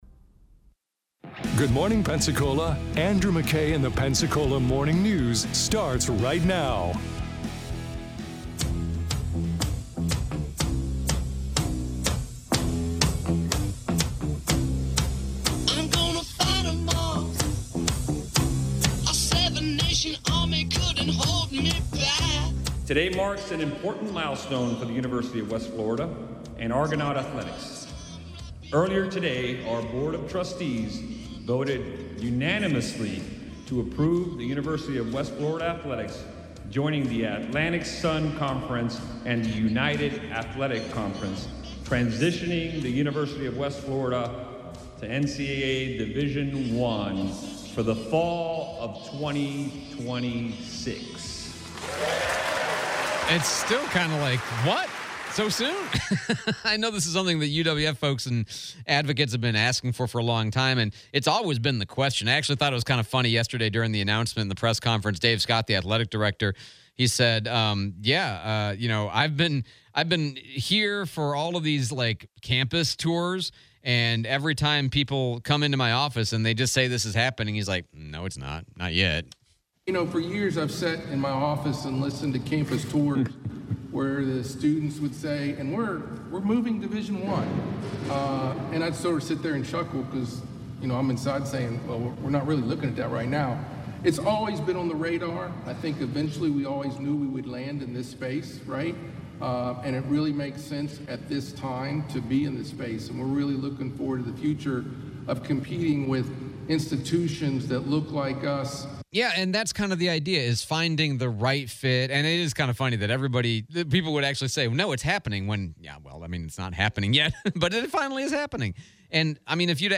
Replay of Interview with former UWF President Judy Bense